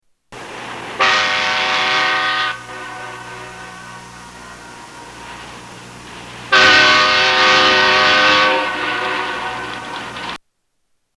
train.mp3